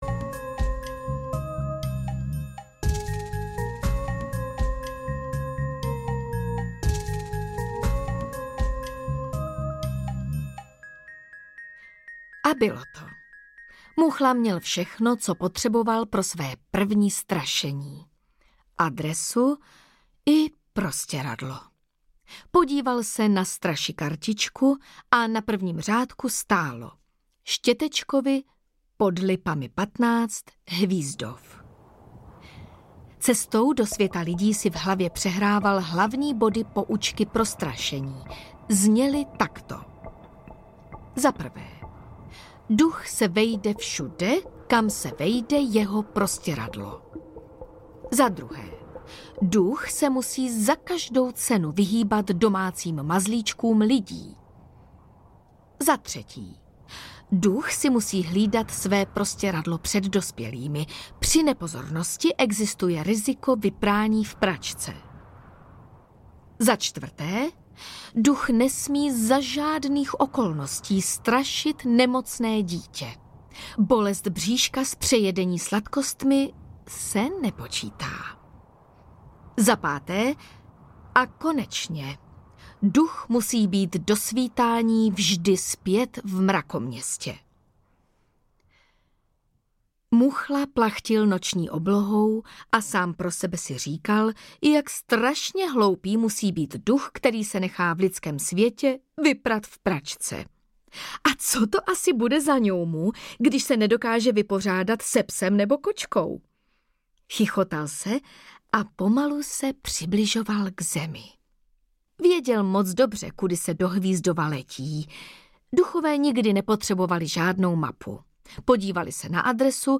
Duch Muchla audiokniha
Ukázka z knihy